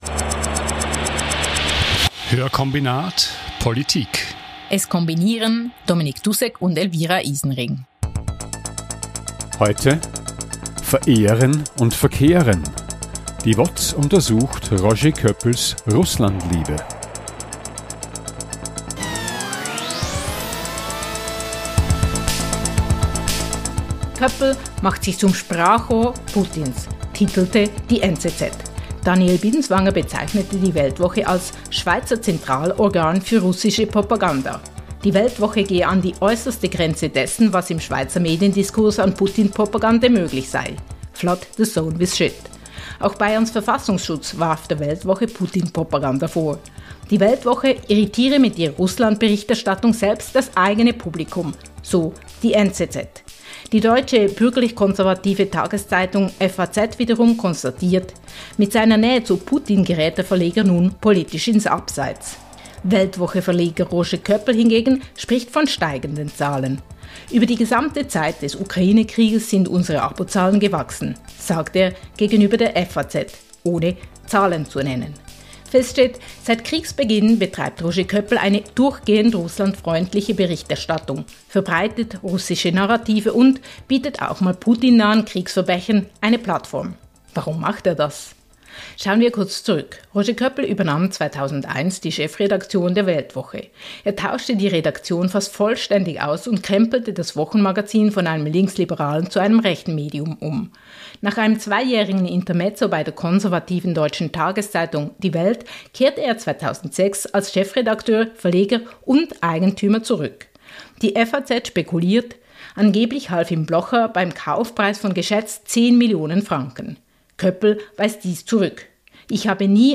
In jeder Folge bespricht das Audio-Duo Hörkombinat einen aktuellen Artikel mit einem/einer Journalist:in und ergänzt das Interview mit Hintergrundinformationen. Der Schwerpunkt liegt auf sozial- und wirtschaftspolitischen Themen.